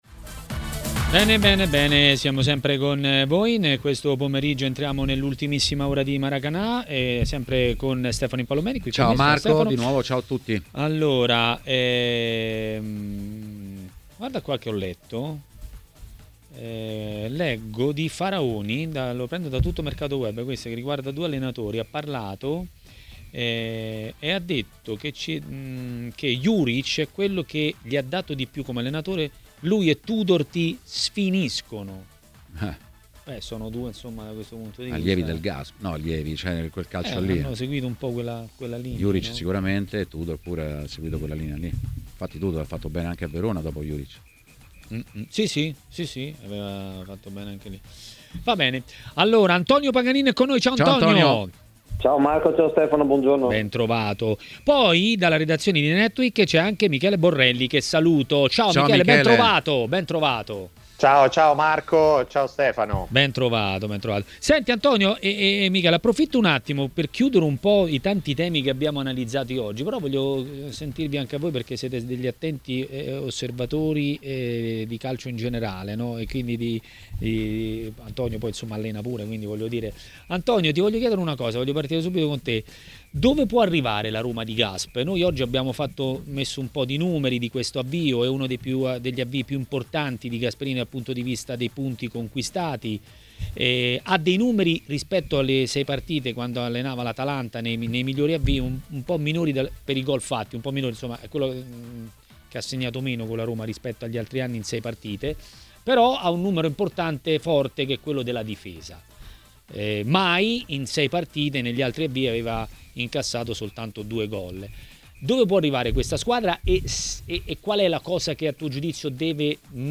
L'ex calciatore Antonio Paganin è stato ospite di Maracanà, trasmissione di TMW Radio.